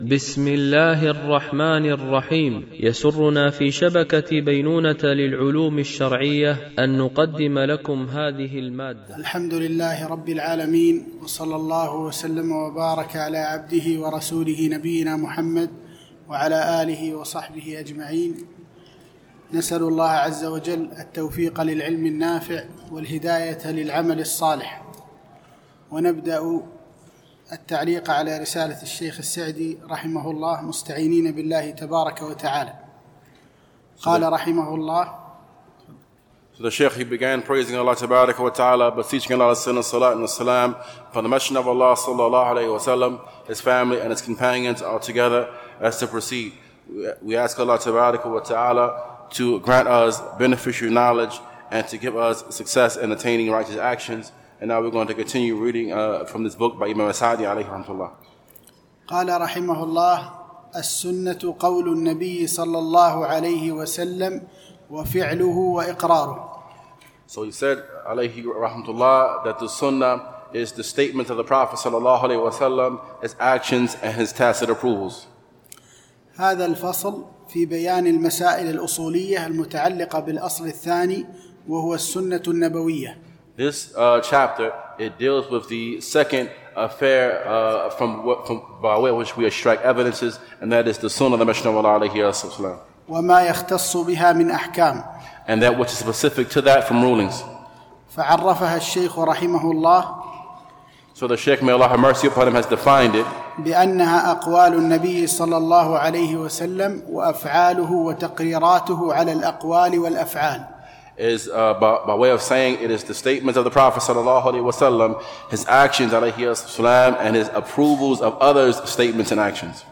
شرح رسالة مختصرة في أصول الفقه لابن سعدي - الدرس 03
بمسجد أم المؤمنين عائشة رضي الله عنها